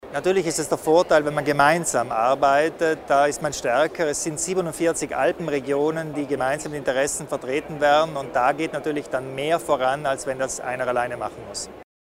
Landeshauptmann Kompatscher über den Beitrag Südtirols zur Makroregion